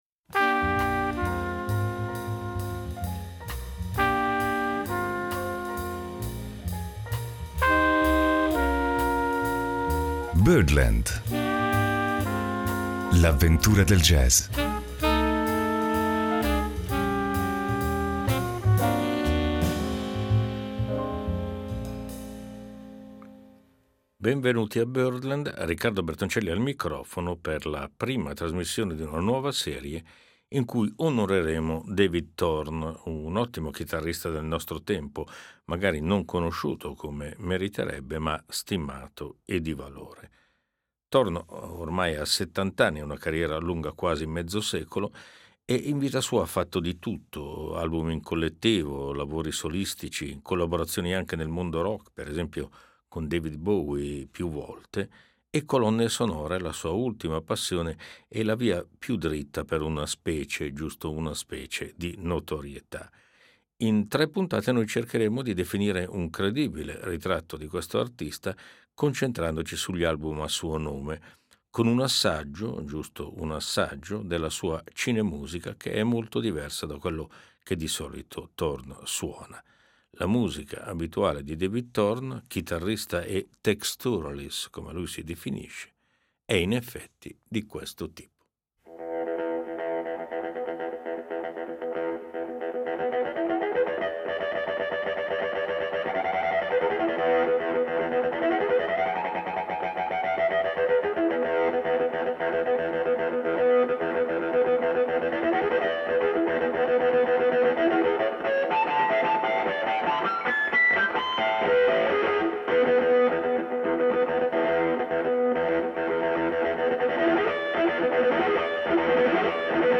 Statunitense, originario dello stato di New York, è considerato come uno dei massimi chitarristi sperimentali, stilisticamente situabile ad un ipotetico punto d’incontro tra Robert Fripp, Henry Kaiser e Bill Frisell. Riccardo Bertoncelli ci guida in un possibile percorso dentro la sua affascinante, sfaccettata musica.